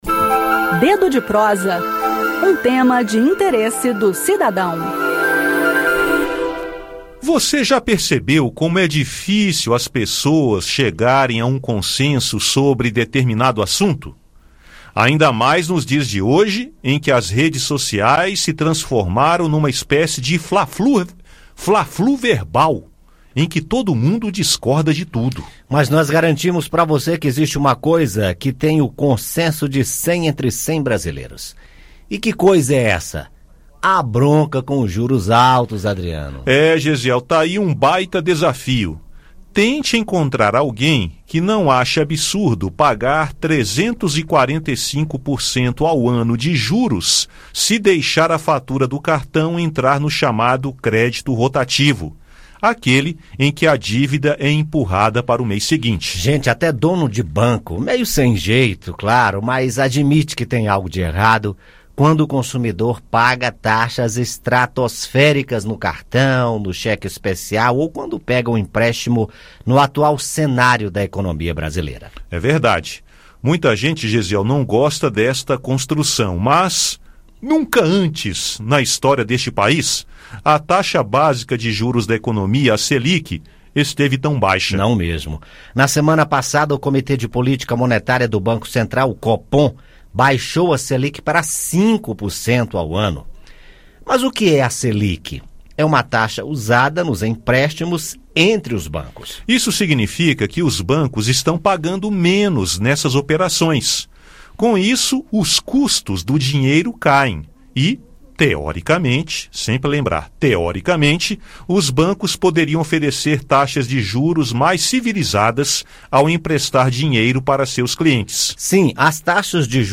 E o assunto do "Dedo de Prosa" desta quarta-feira (6) são os juros cobrados dos brasileiros pelo sistema bancário e pelas administradoras de cartões de crédito. Ouça o áudio com o bate-papo.